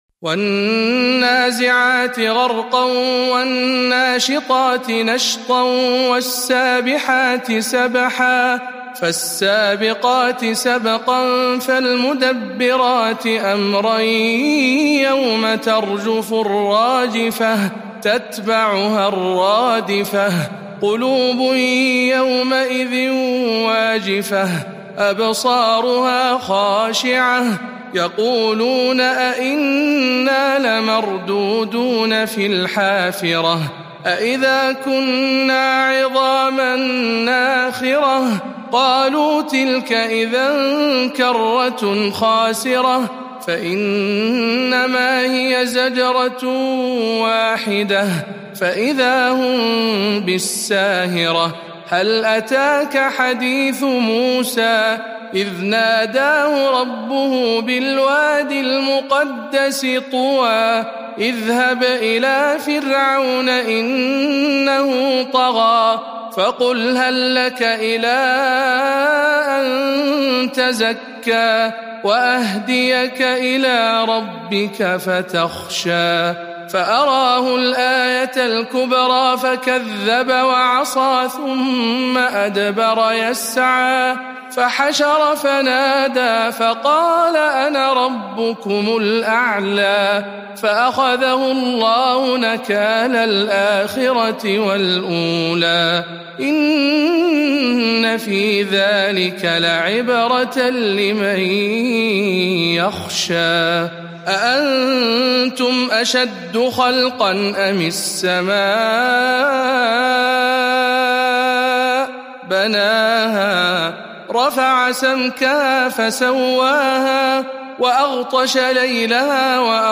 078. سورة النازعات برواية شعبة عن عاصم